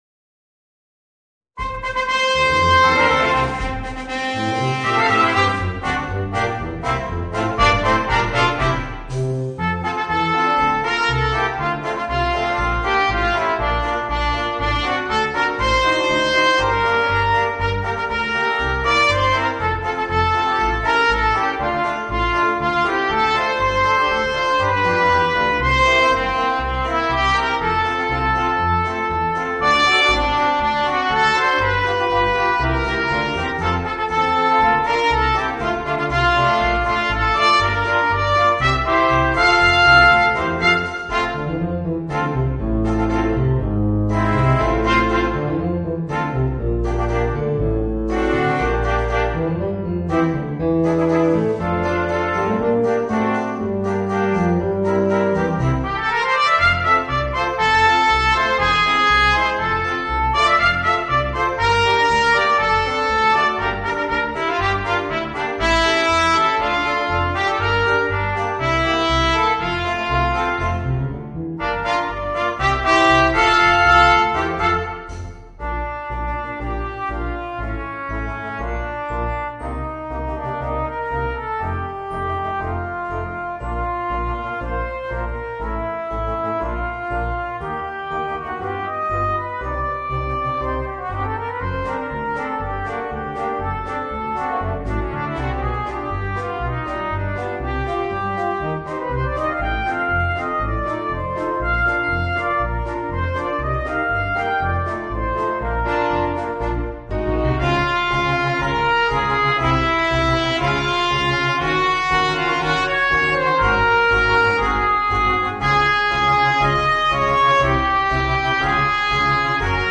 Voicing: Small Ensembles